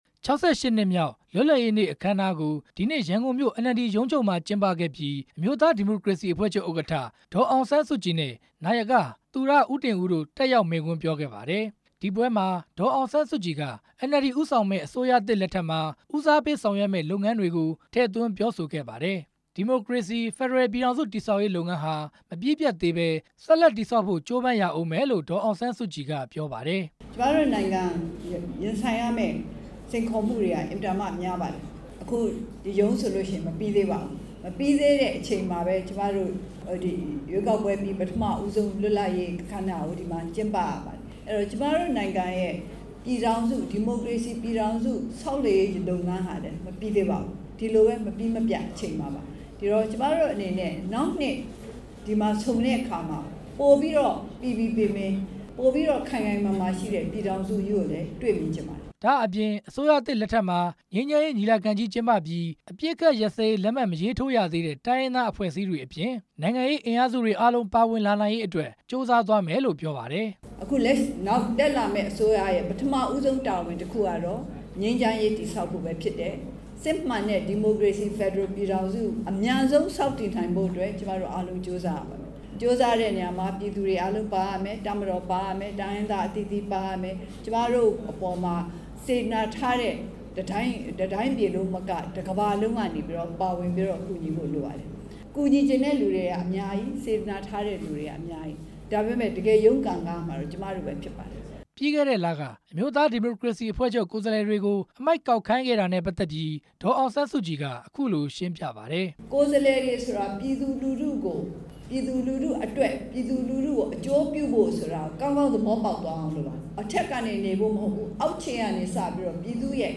၆၈ နှစ်မြောက်လွတ်လပ်ရေးနေ့အခမ်းအနားကို ရန်ကုန်မြို့ အမျိုးသားဒီမိုကရေစီ အဖွဲ့ချုပ် NLD ရုံးချုပ်မှာ ကျင်းပခဲ့ပြီး ဒေါ်အောင်ဆန်းစုကြည် တက်ရောက် မိန့်ခွန်းပြောကြားခဲ့ပါတယ်။